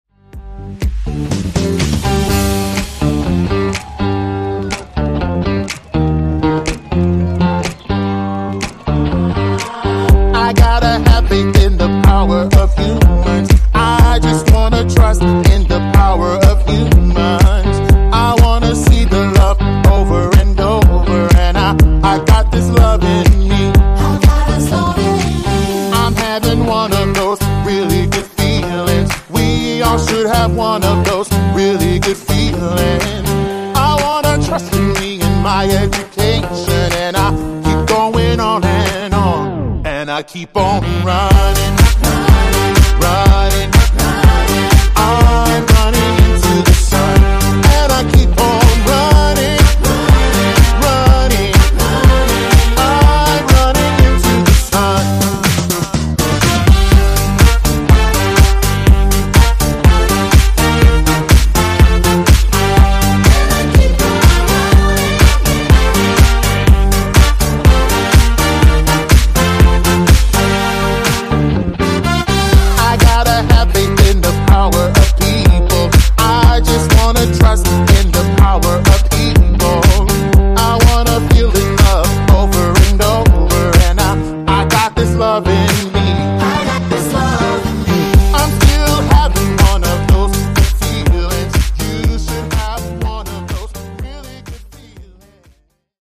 Genres: DANCE , EDM , RE-DRUM Version: Clean BPM: 124 Time